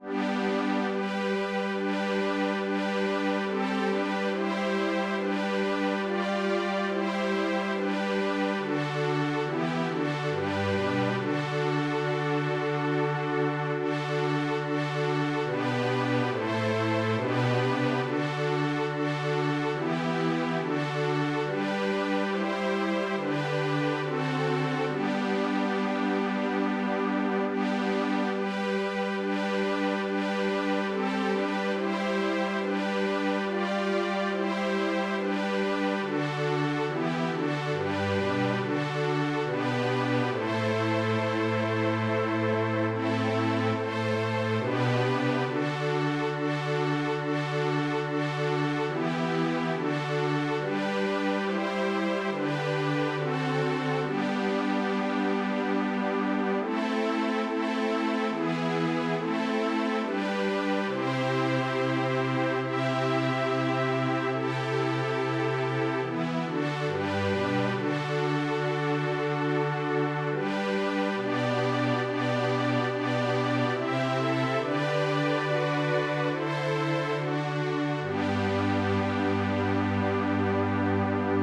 Задостойник Рождества Христова в стихотворном переложении для смешанного хора на русском языке: перевод Архиепископа Тульчинского и Брацлавского Ионафана (Елецких),